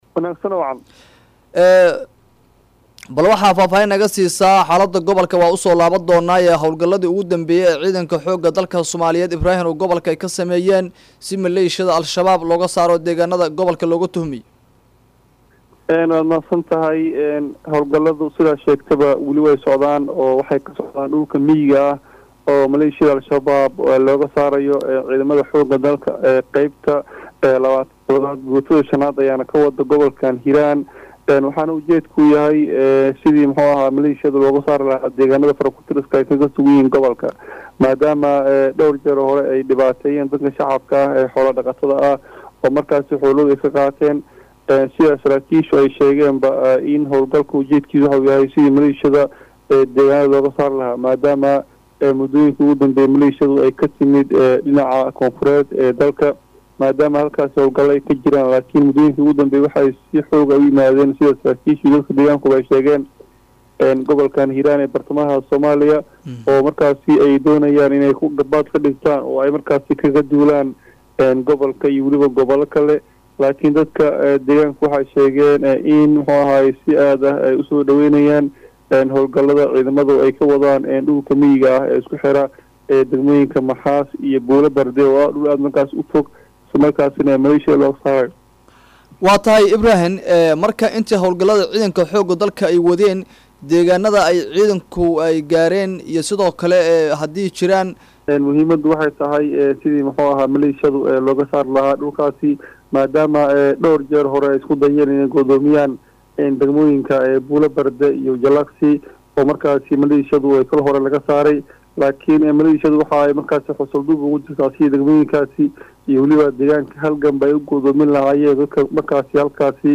Wareysiyada